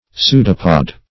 Pseudopod \Pseu"do*pod\, n. [Pseudo- + -pod.]